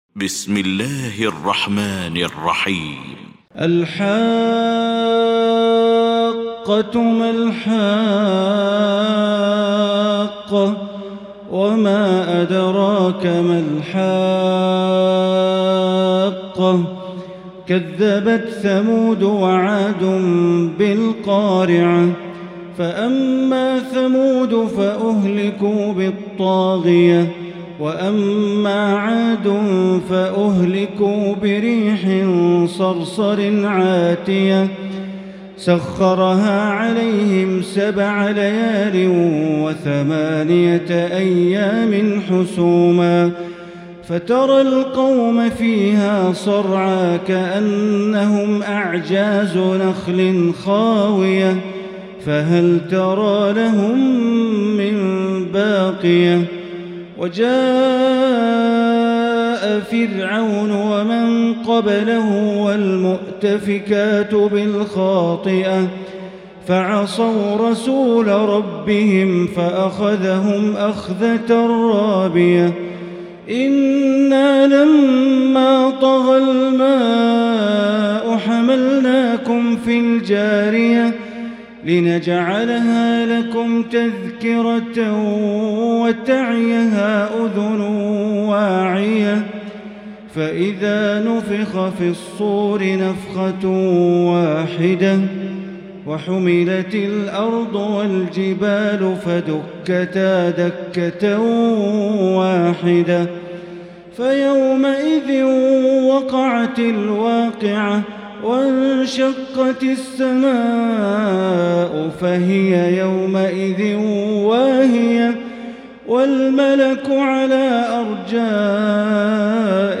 المكان: المسجد الحرام الشيخ: معالي الشيخ أ.د. بندر بليلة معالي الشيخ أ.د. بندر بليلة الحاقة